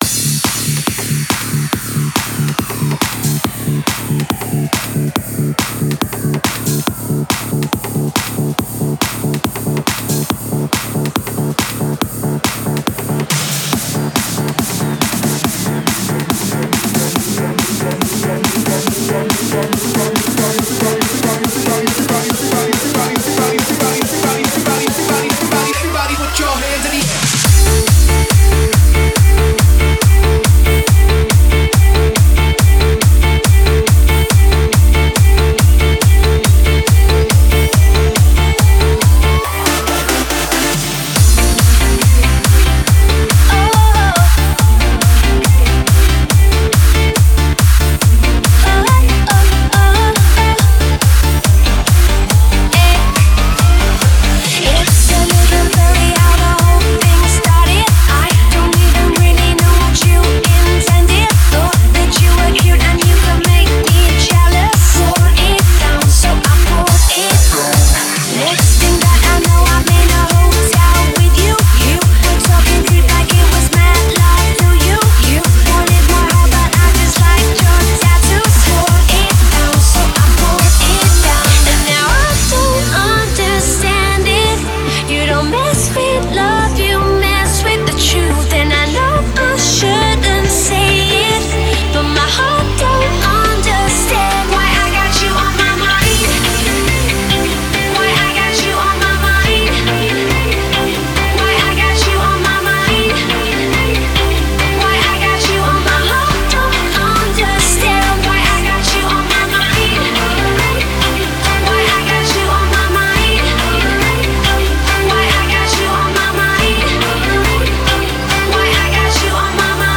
a Hands Up song